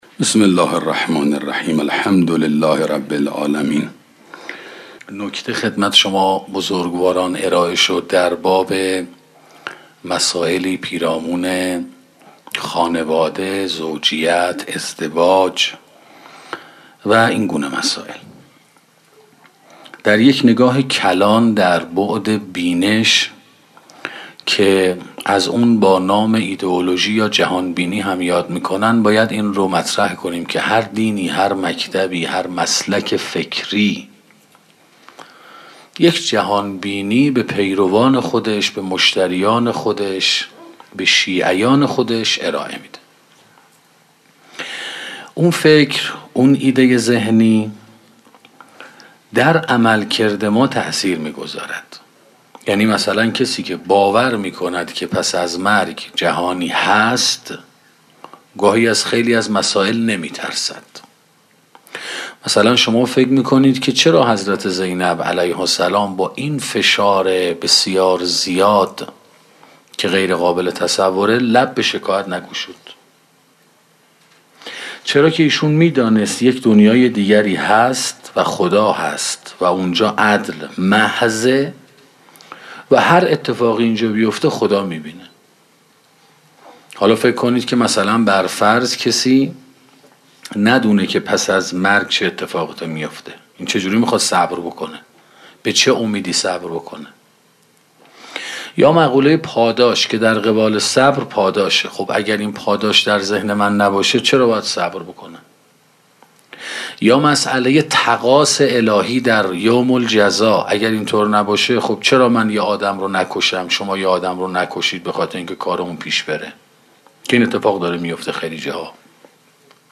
سخنرانی خانواده و تربیت فرزند 4